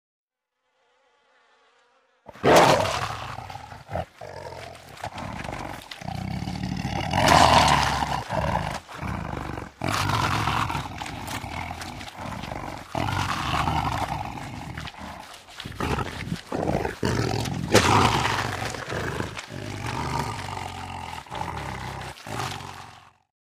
Звуки животных
Рычание льва, пожирающего плоть добычи